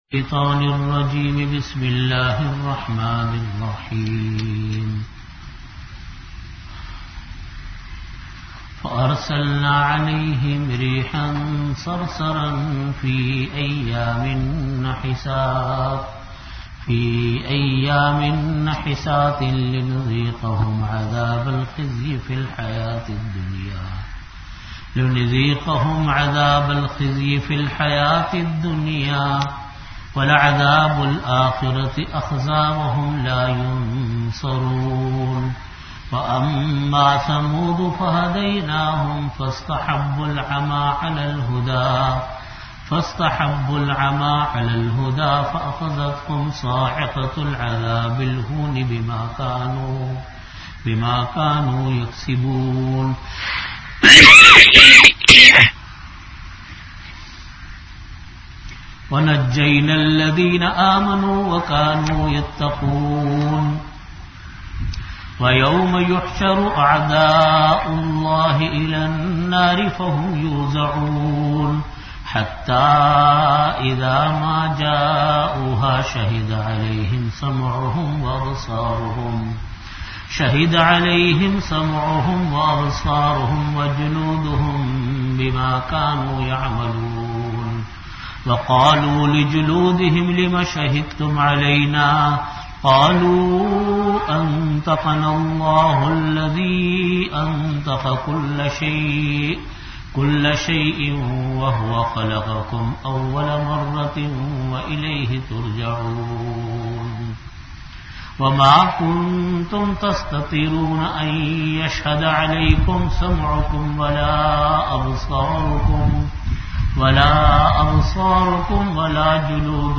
Originally delivered in After Asar Prayer at Jamia Masjid Bait-ul-Mukkaram, Karachi.
Venue: Jamia Masjid Bait-ul-Mukkaram, Karachi